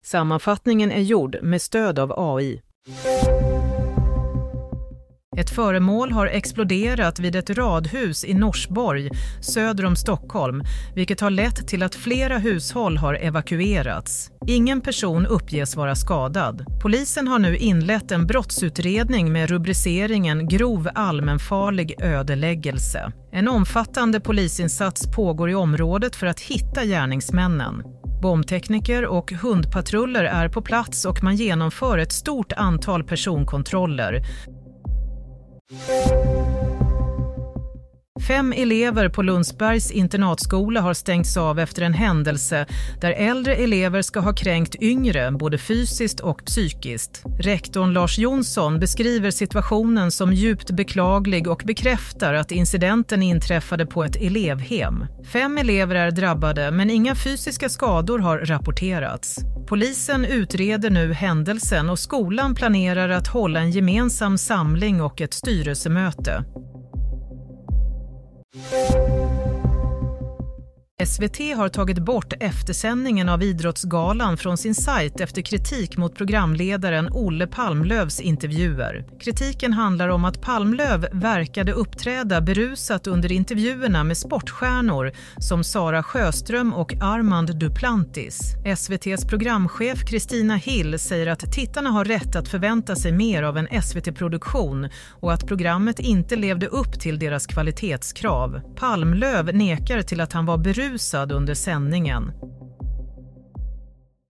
Nyhetssammanfattning – 22 januari 07.30
Sammanfattningen av följande nyheter är gjord med stöd av AI.